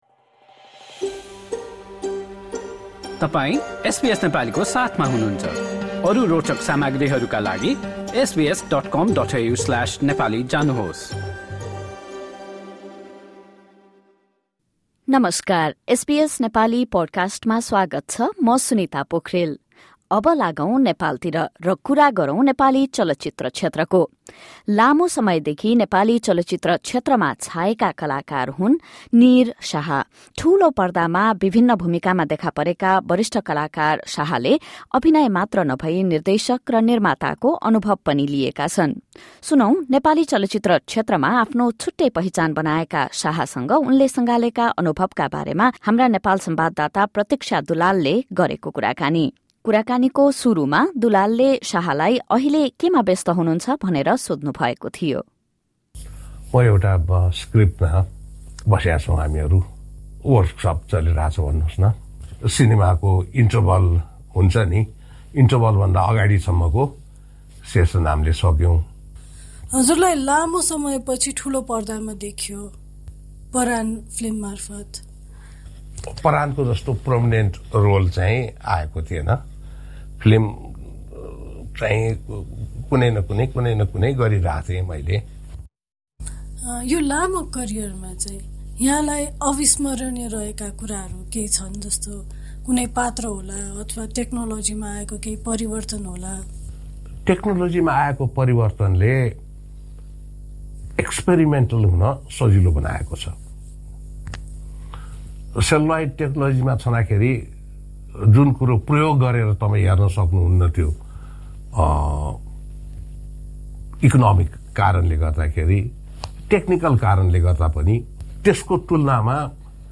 Veteran Nepali actor Neer Shah speaking with SBS Nepali.